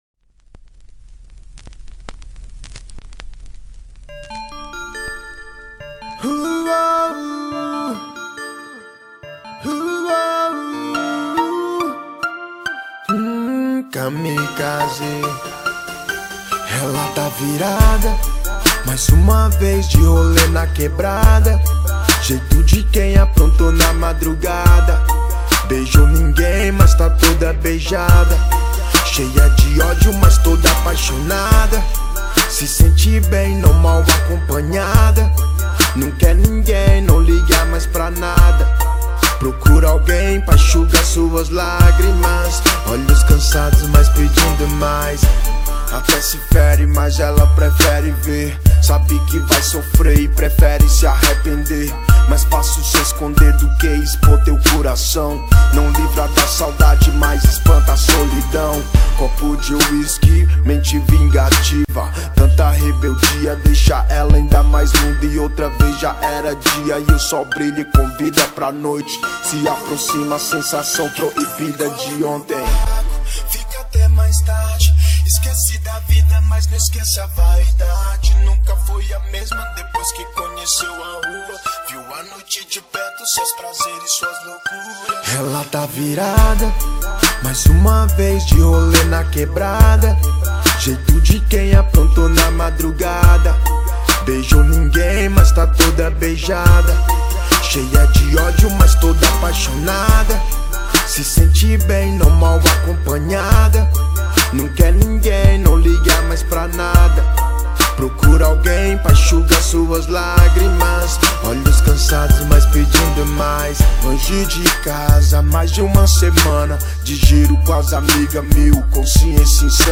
2025-02-23 16:17:07 Gênero: Rap Views